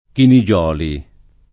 Chinigioli [ kini J0 li ] cogn.